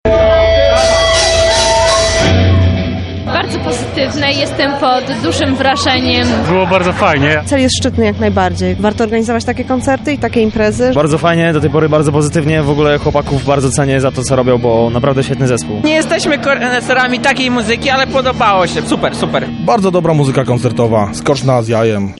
koncert charytatywny
W trakcie imprezy zagrały dwie formacje : Dwudziestu Siedmiu Bezimiennych oraz Madvision.
MadVision-koncert.mp3